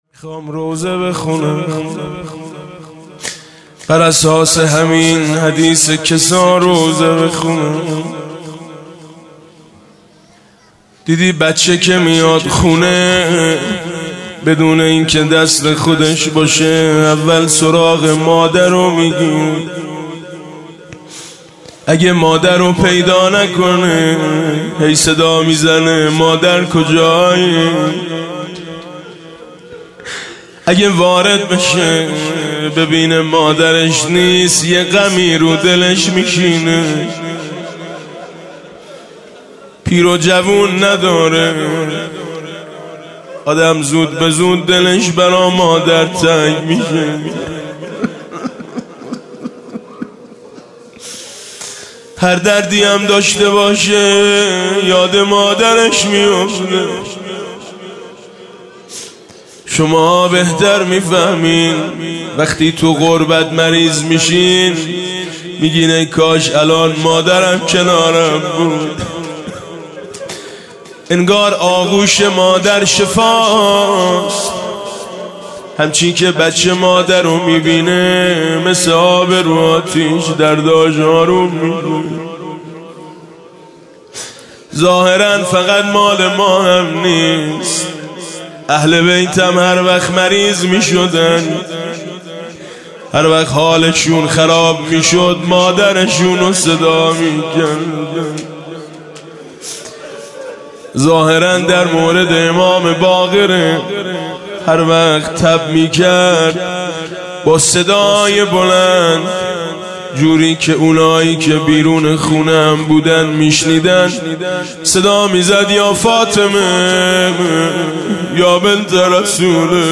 18 اردیبهشت 98 - هیئت میثاق با شهدا - روضه حضرت زهرا